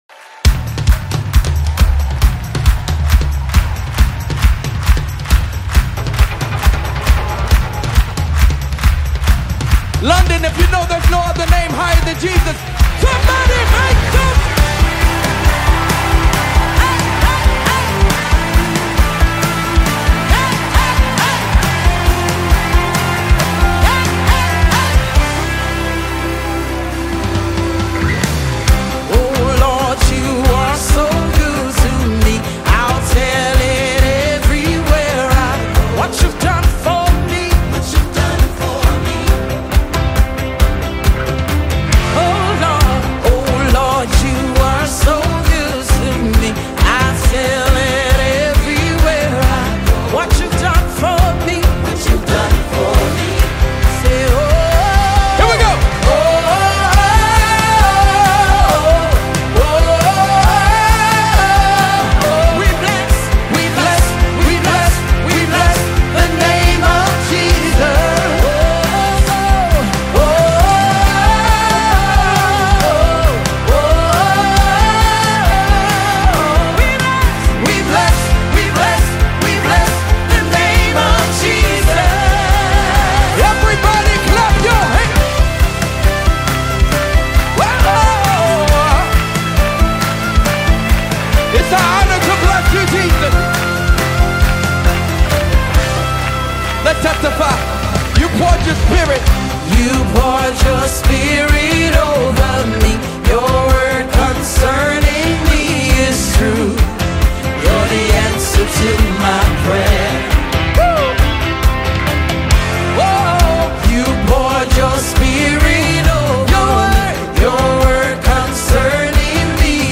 praise anthem